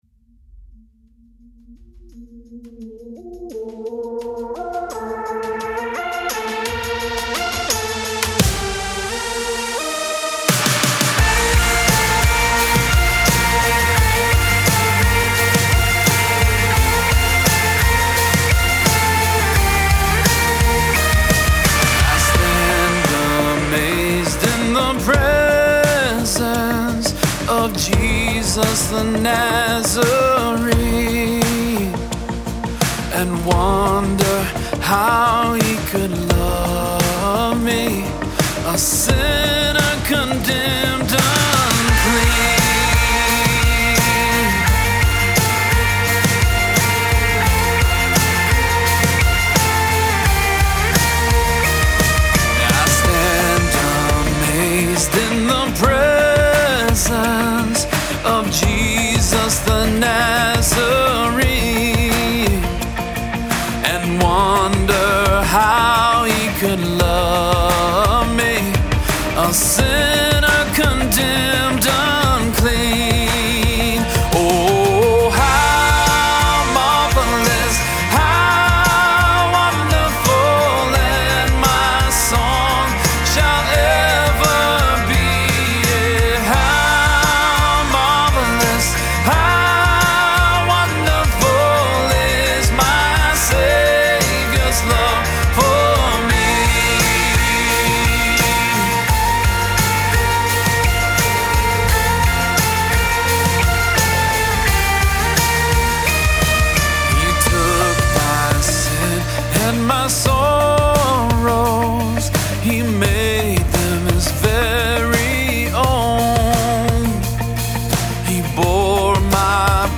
Full arrangement demo
• Keys: Bb, G, A
• Tempo: 86 bpm, 4/4 time
• Drums
• Percussion
• Acoustic Guitar
• Bass
• Electric Guitars (2 tracks)
• Gang Vocals
• Lead Synths
• Piano
• Synth Bass